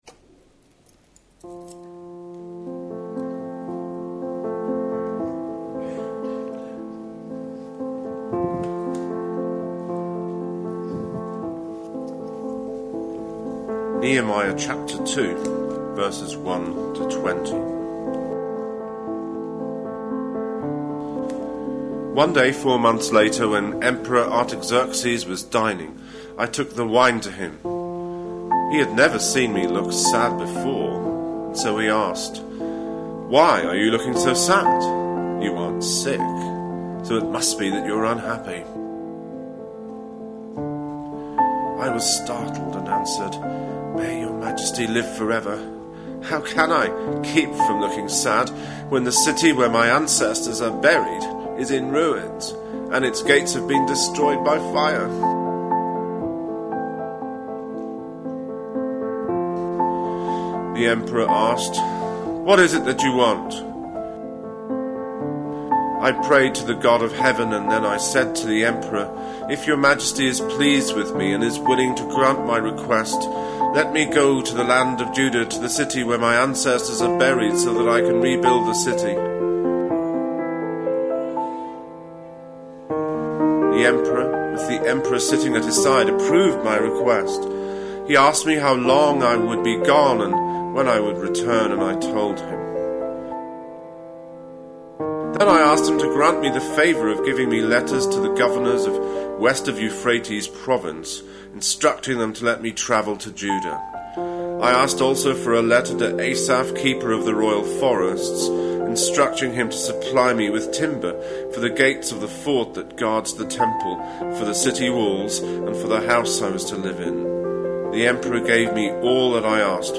A sermon preached on 22nd June, 2014, as part of our Another Brick In The Wall. series.